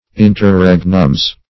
Interregnum \In`ter*reg"num\, n.; pl.